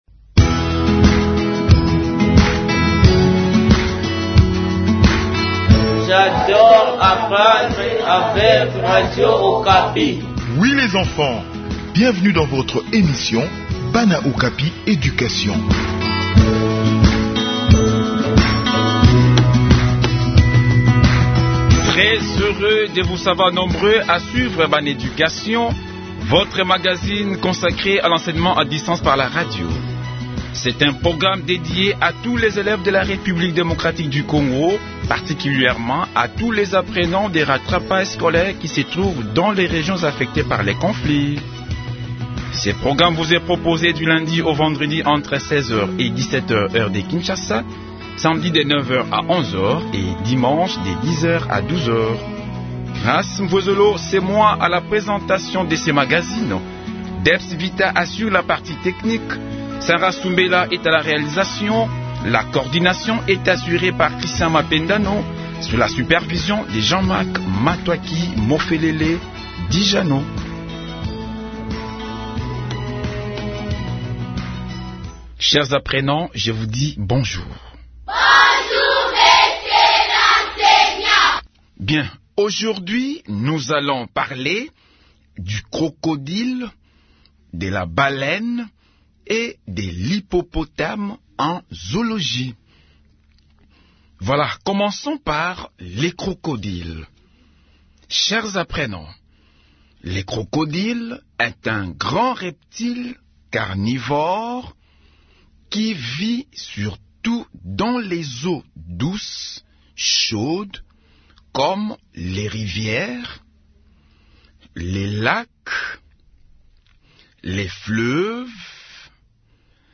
Réécoutez cette leçon pour explorer leur importance écologique.